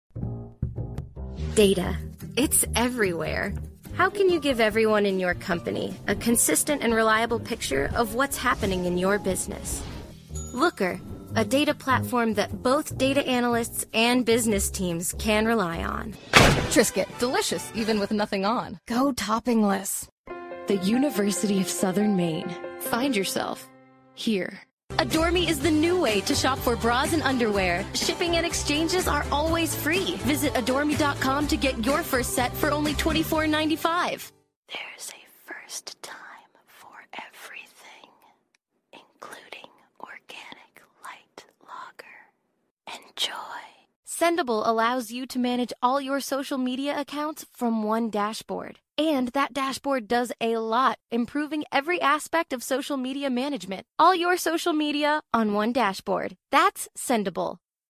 voiceover : animation